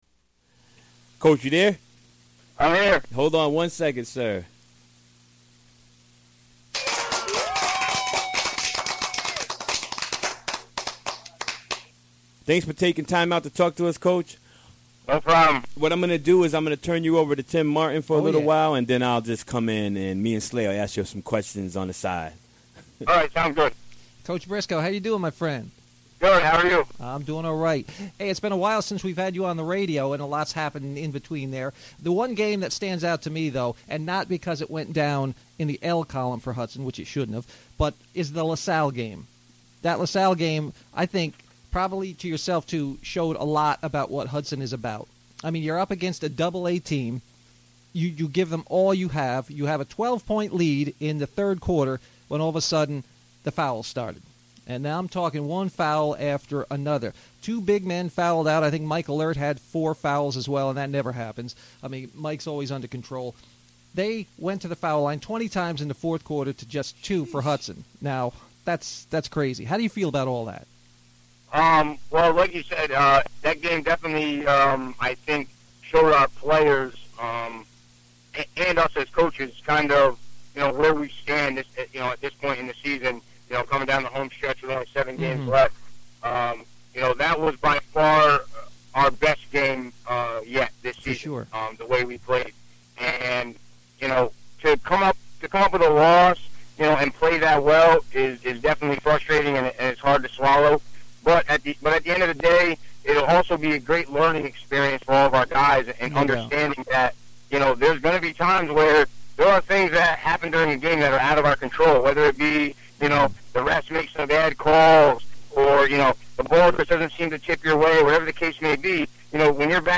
Recorded during the WGXC Afternoon Show Wednesday, January 25, 2017.